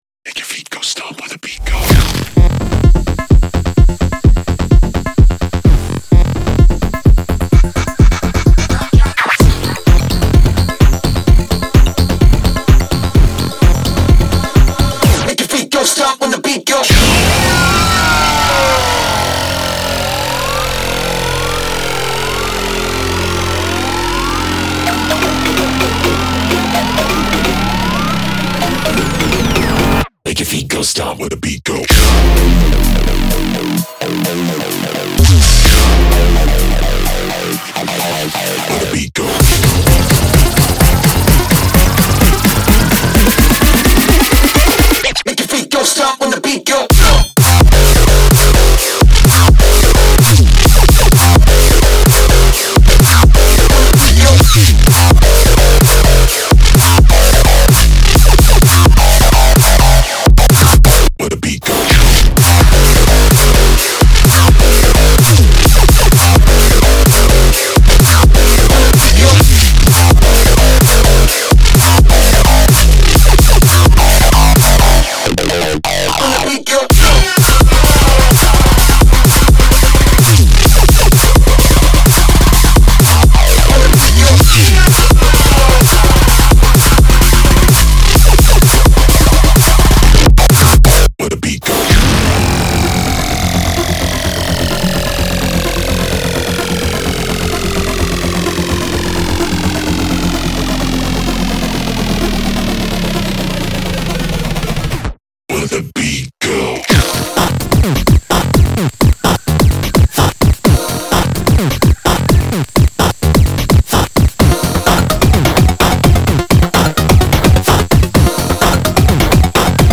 BPM128-128
Audio QualityPerfect (High Quality)
Bass House song for StepMania, ITGmania, Project Outfox
Full Length Song (not arcade length cut)